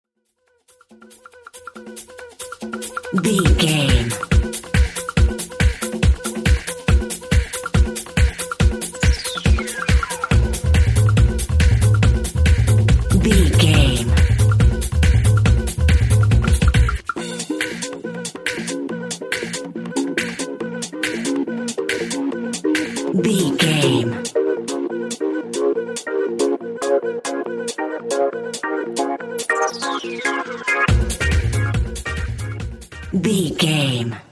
90s Rave Music.
Aeolian/Minor
B♭
groovy
uplifting
driving
energetic
repetitive
drum machine
synthesiser
house
techno
acid
synth lead
synth bass